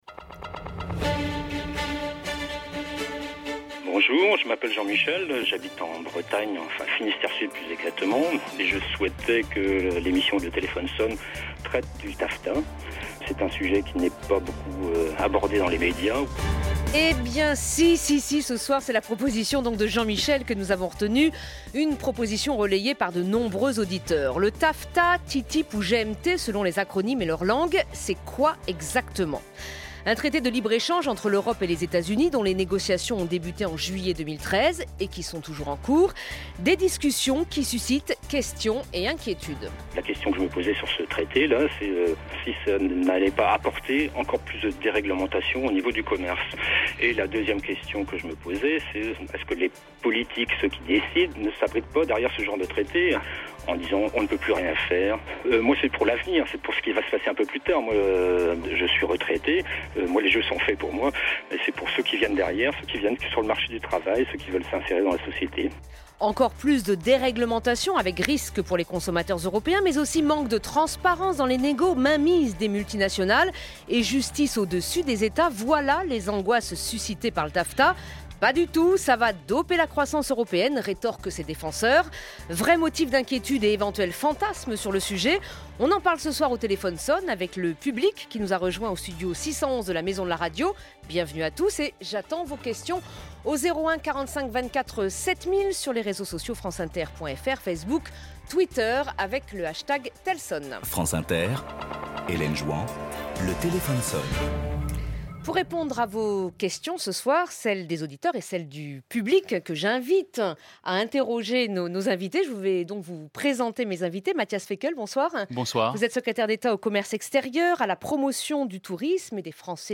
Avec comme invités : Matthias Fekl , secrétaire d'Etat chargé du Commerce Extérieur, de la promotion du Tourisme, et des Français de l'étranger